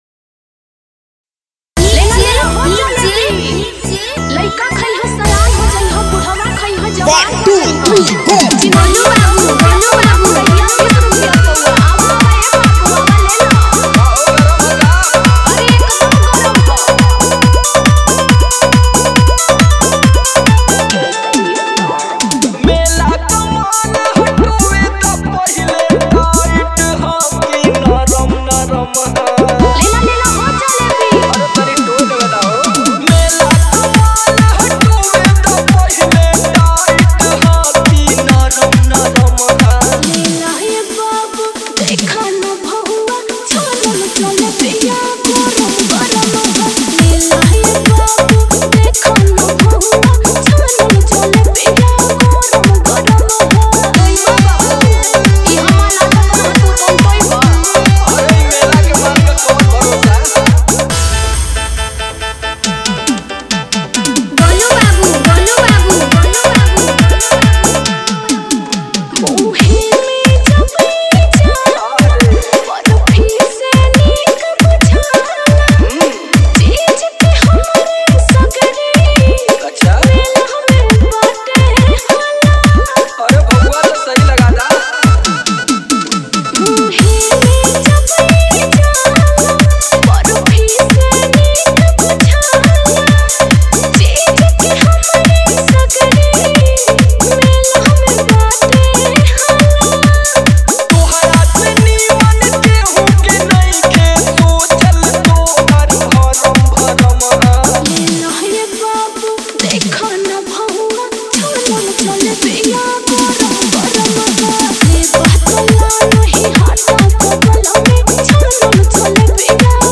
Bhakti Dj Songs